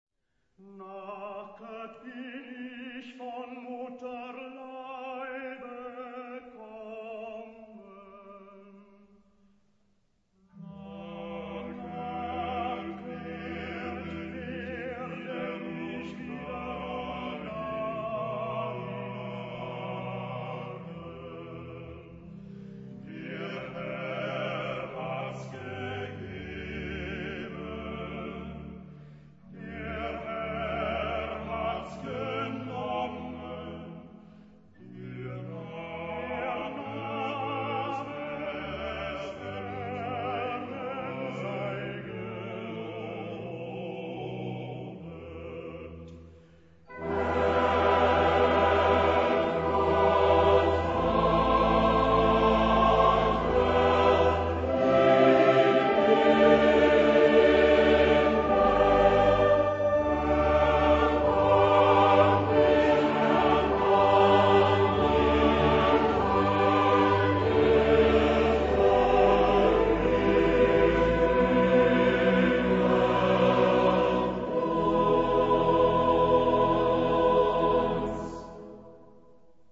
SSATTB (6 voix mixtes).
Sacré. Baroque. Messe.
Solistes : SSATTB/B (7 soliste(s)) Instruments : Basse continue